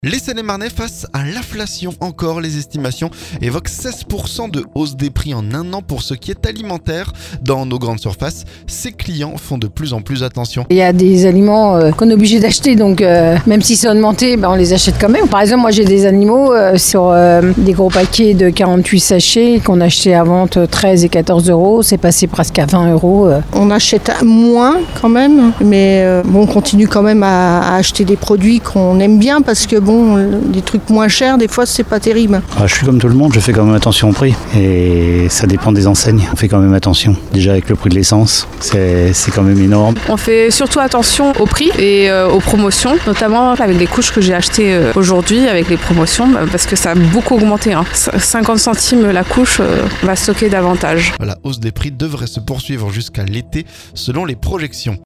INFLATION - Les prix de l'alimentaire explosent, reportage dans un supermarché